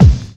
Shady_Kick_5.wav